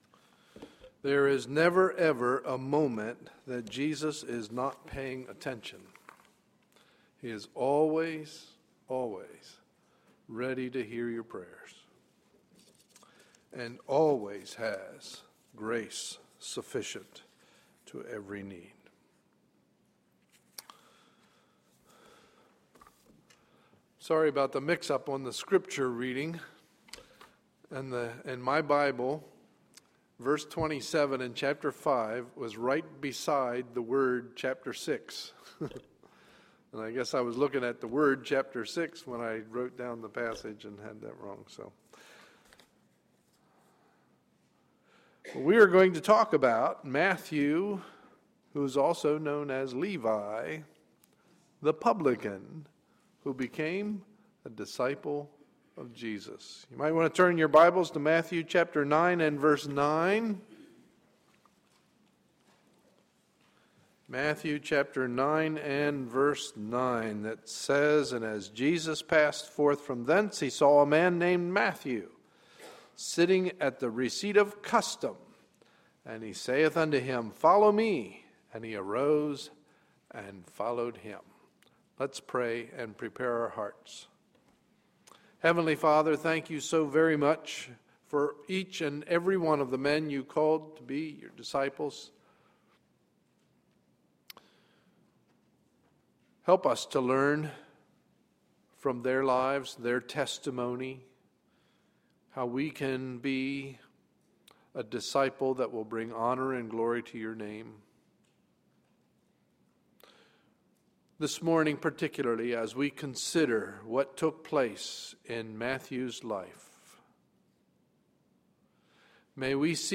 Sunday, July 29, 2012 – Morning Message